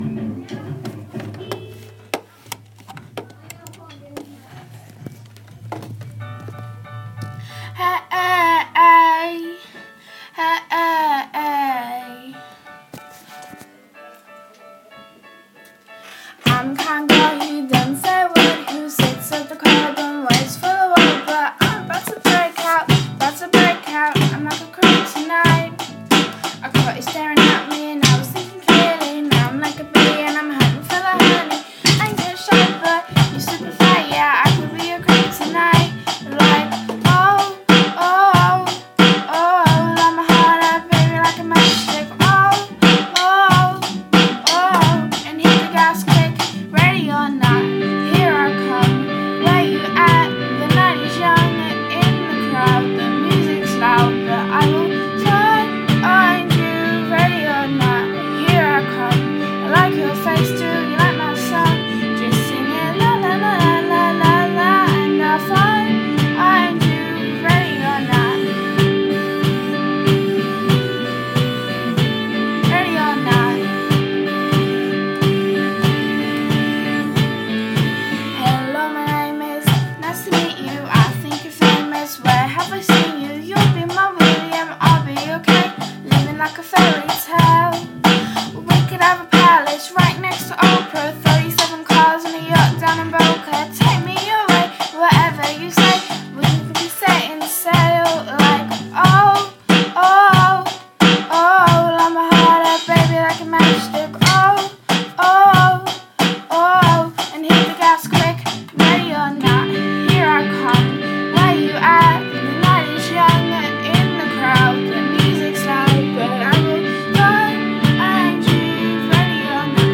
ready or not with drums without click